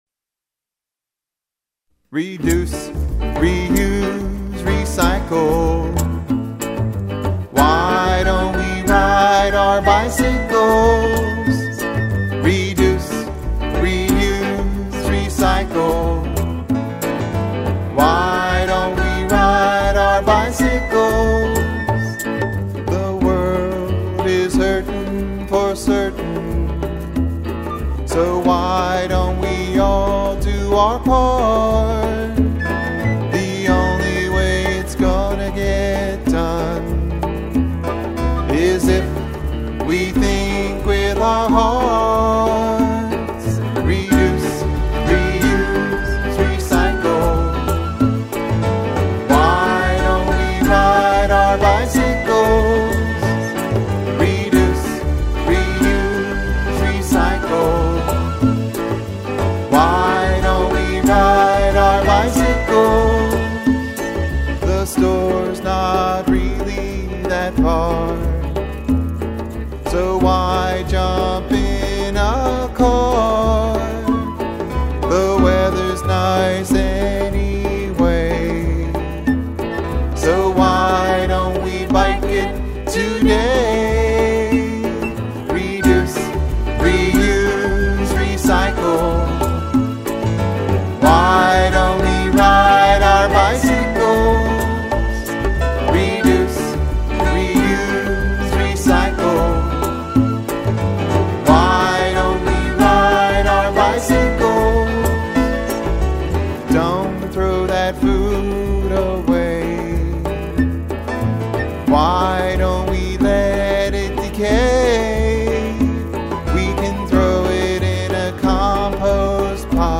Music